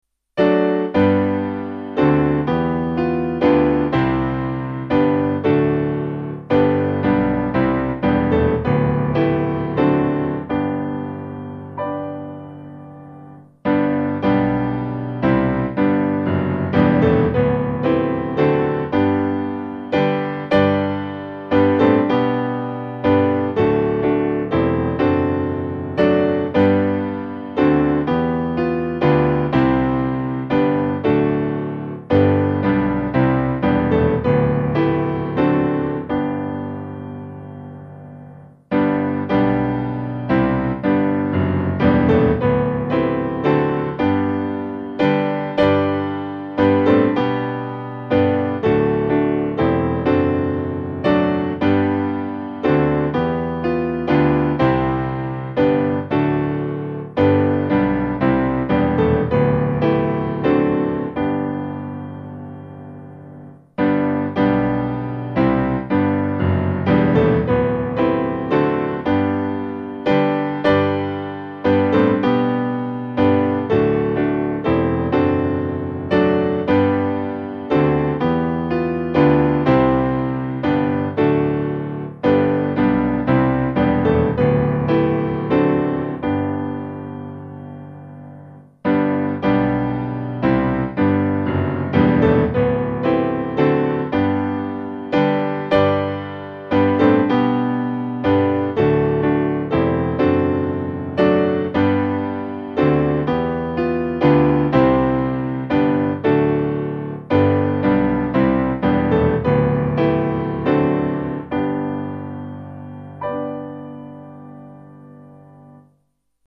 Traditional hymn